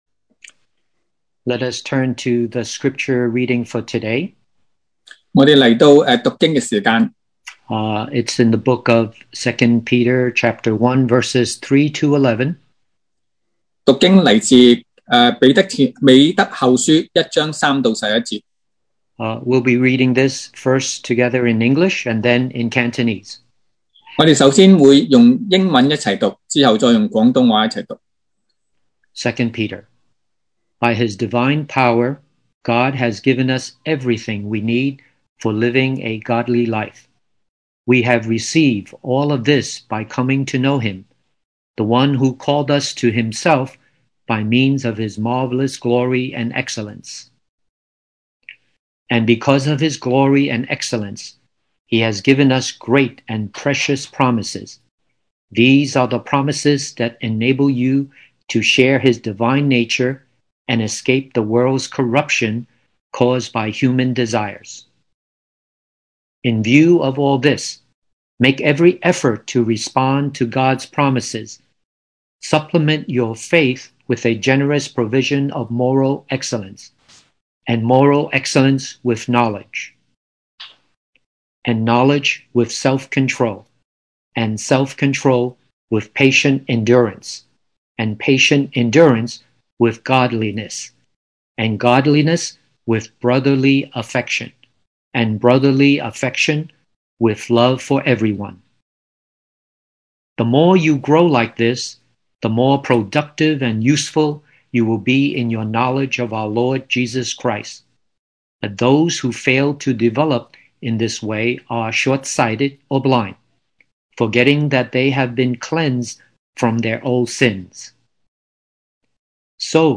sermon audios
Service Type: Sunday Morning